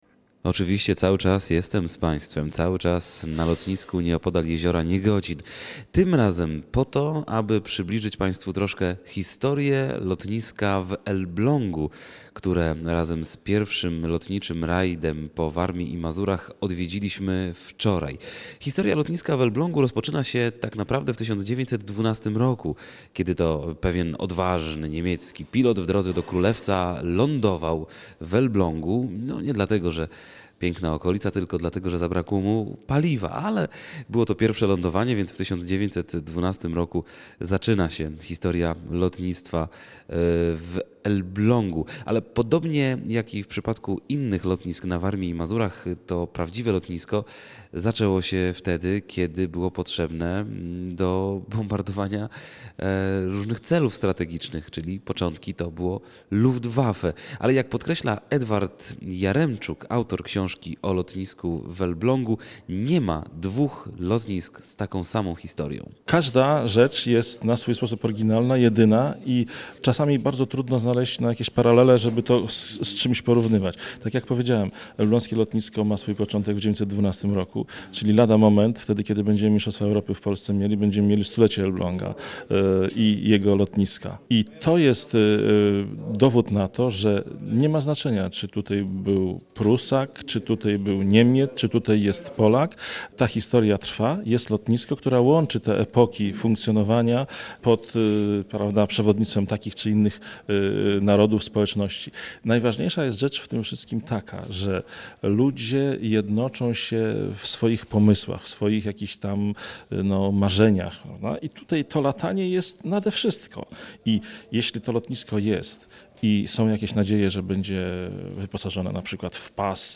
2009-06-13Relacje z Rajdu po Lotniskach i Lądowiskach Warmii i Mazur - Elbląg, cz. 1 (źródło: Radio Olsztyn)